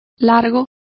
Complete with pronunciation of the translation of lengthy.